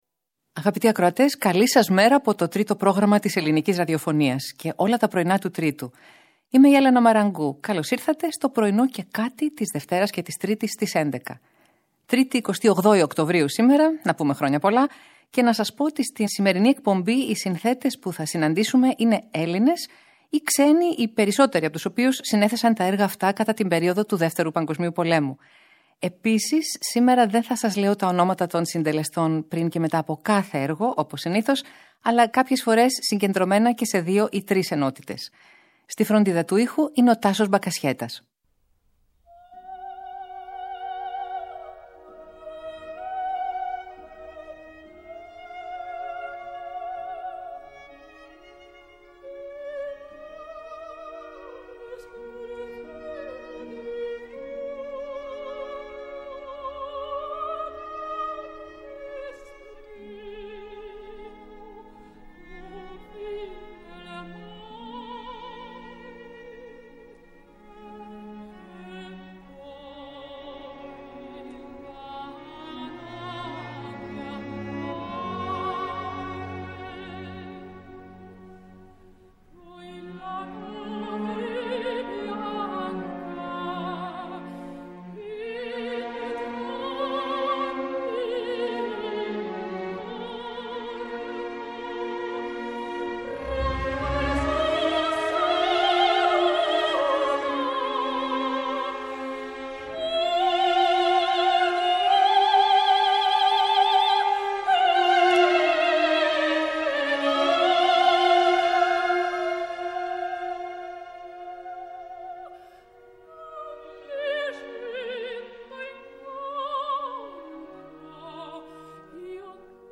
Ερμηνείες από όλες τις εποχές και μερικές ανατροπές συνοδεύουν τις μικρές και μεγάλες εικόνες της ημέρας.